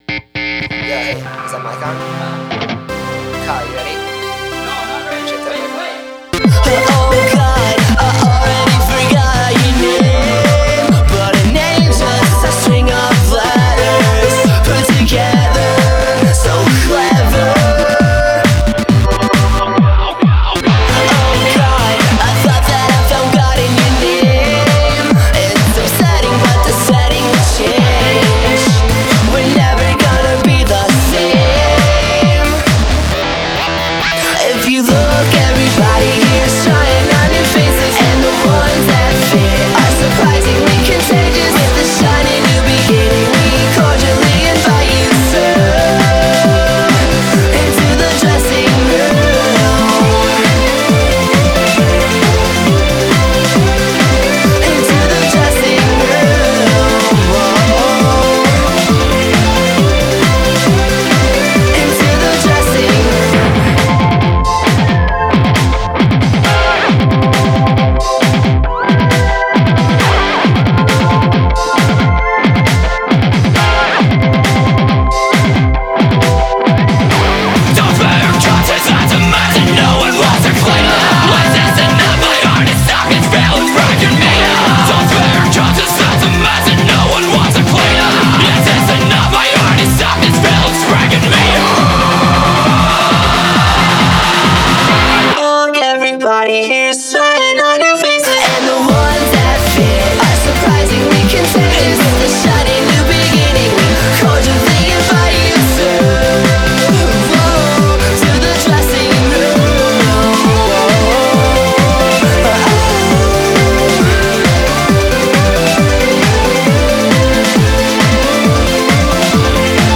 BPM135
Enjoy some 2009 electronicore.